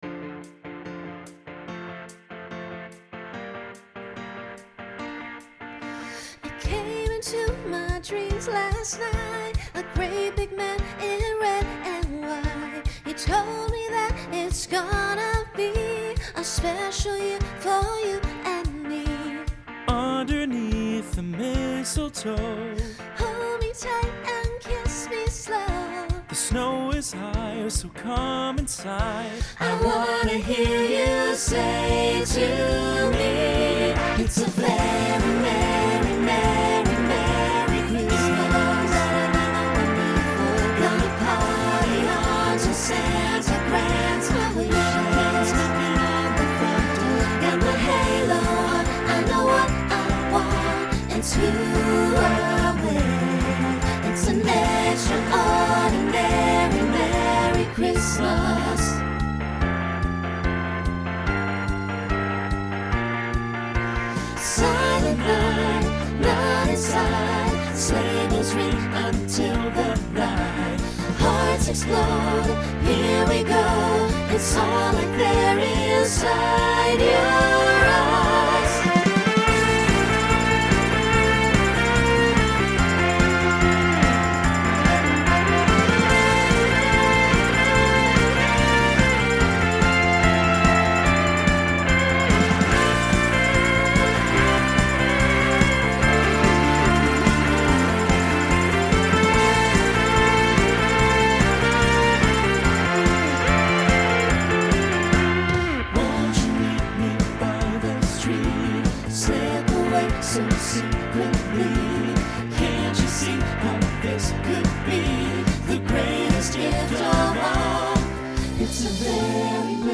Holiday , Pop/Dance Instrumental combo
Opener Voicing SATB